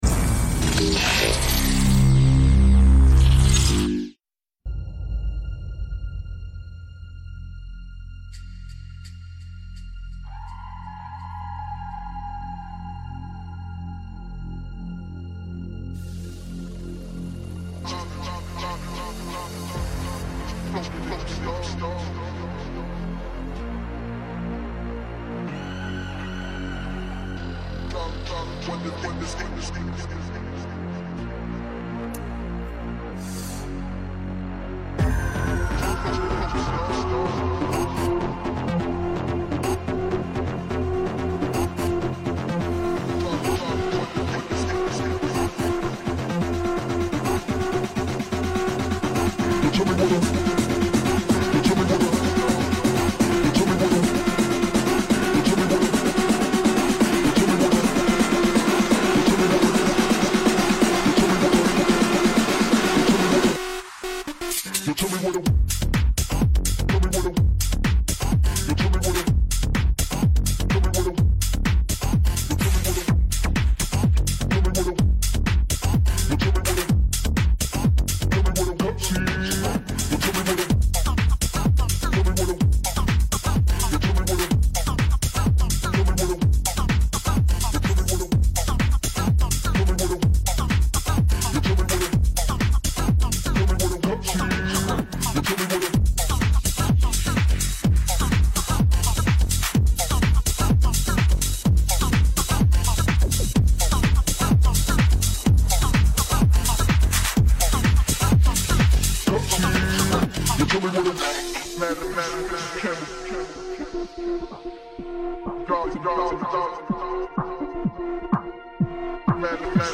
Quarantine Livestreams Genre: House